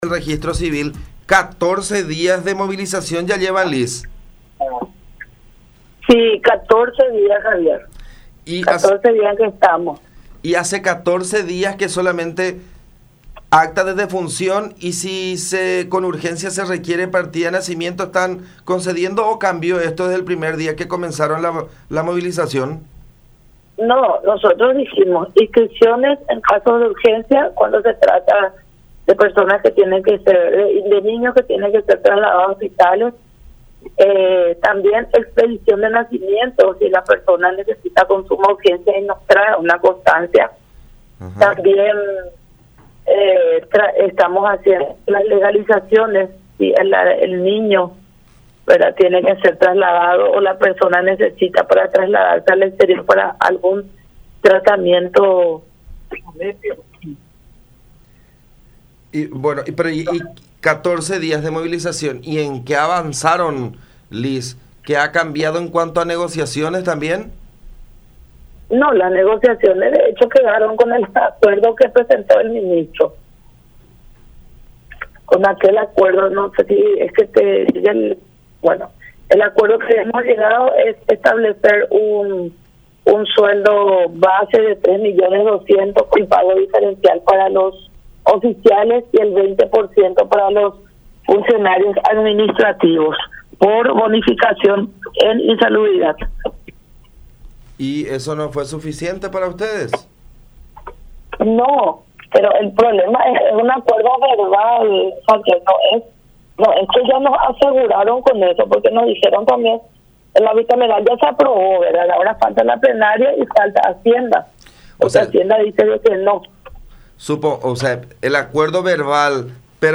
en comunicación con La Unión.